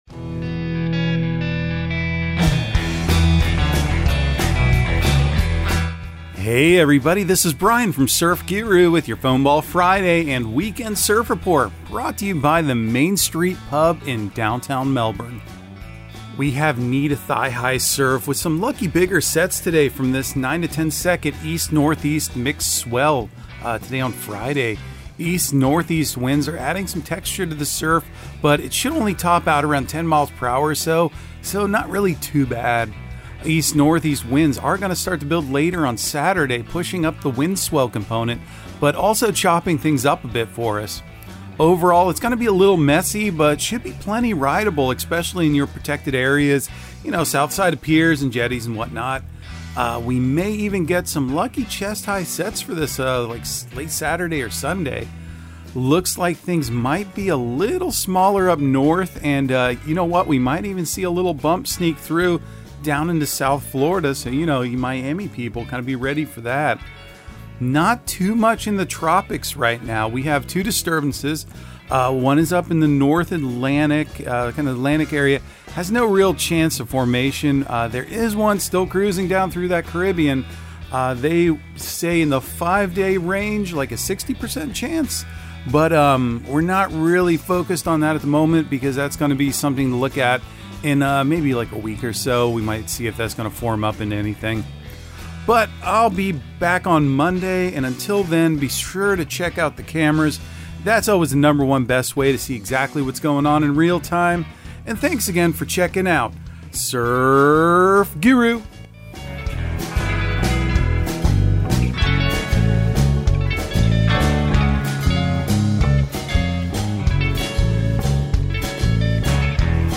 Surf Guru Surf Report and Forecast 10/28/2022 Audio surf report and surf forecast on October 28 for Central Florida and the Southeast.